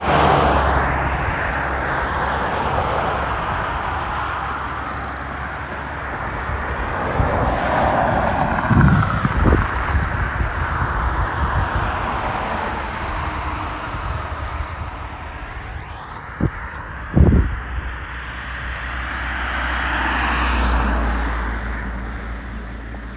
Tropical Kingbird,  Tyrannus melancholicus